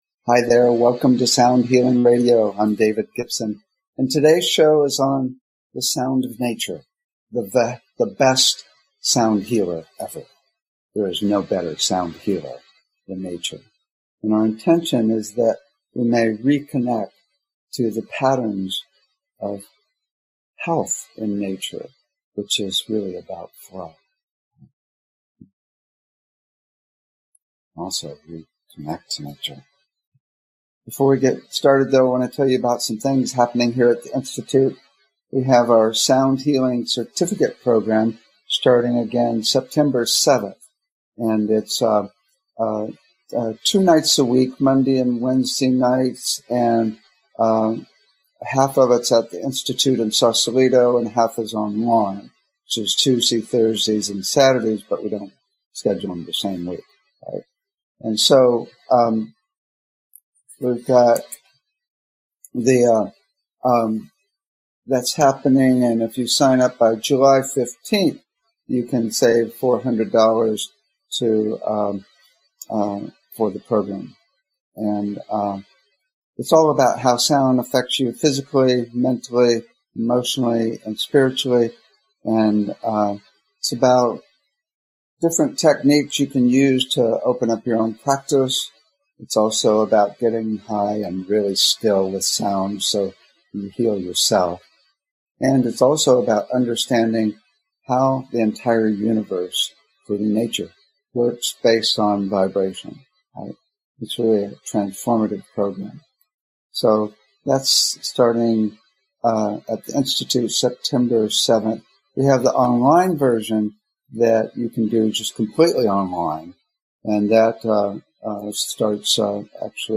Talk Show Episode, Audio Podcast, Sound Healing and The Sound of Nature, the best sound healer ever on , show guests , about The Sound of Nature,the best sound healer ever, categorized as Education,Health & Lifestyle,Sound Healing,Kids & Family,Philosophy,Psychology,Emotional Health and Freedom,Self Help,Spiritual